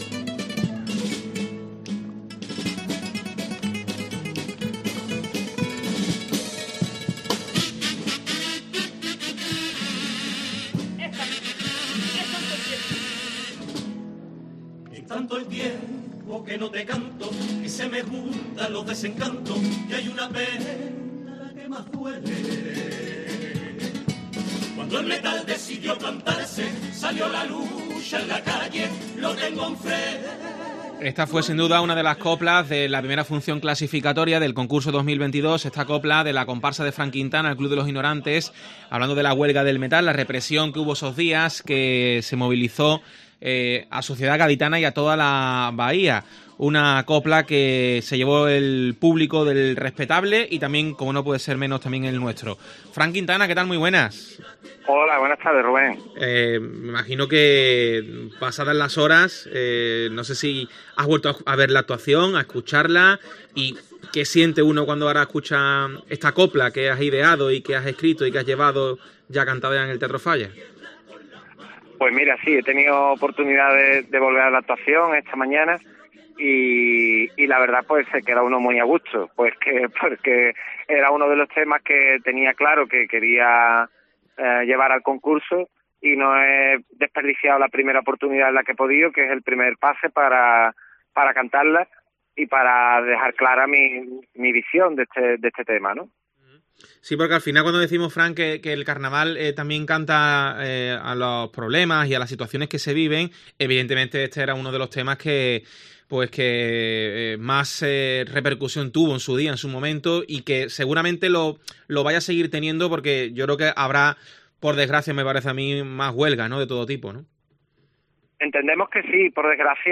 El comparsista analiza su pase de clasificatorias con la comparsa 'El club de los ignorantes'